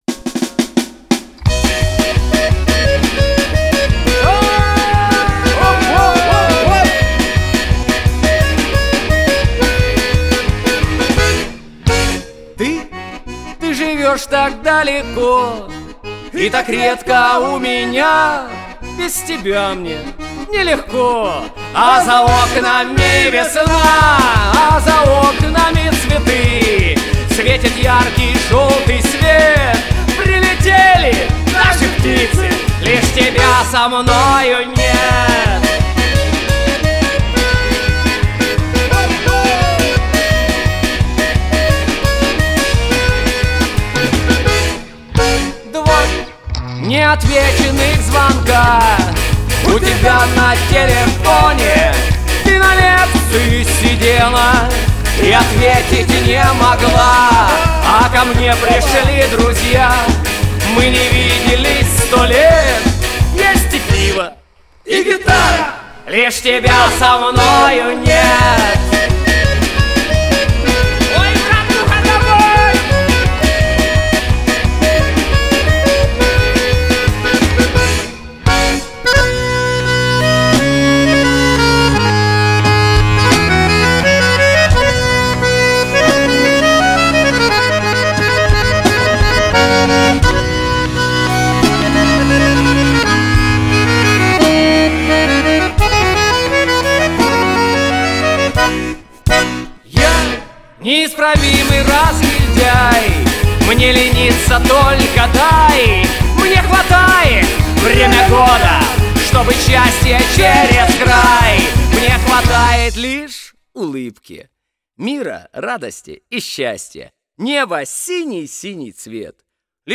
Соло на баяне